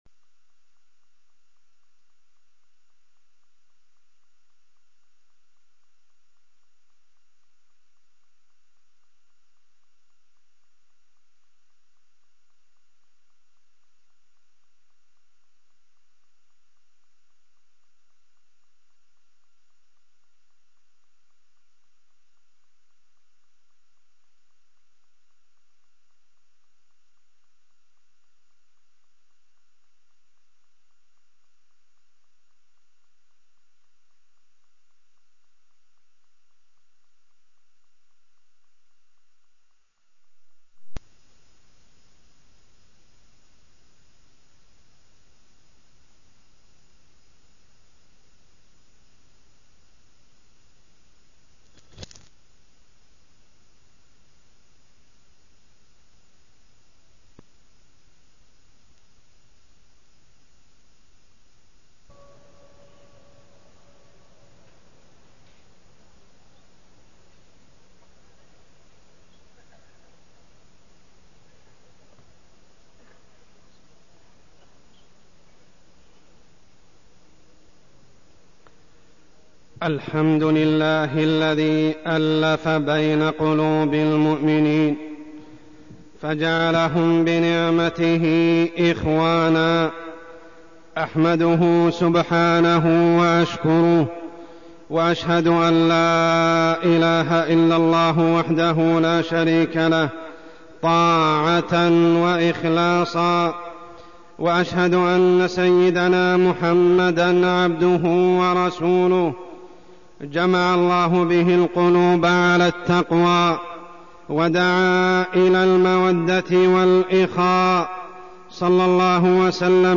تاريخ النشر ١٥ ذو القعدة ١٤١٨ هـ المكان: المسجد الحرام الشيخ: عمر السبيل عمر السبيل أمة التوحيد والإيمان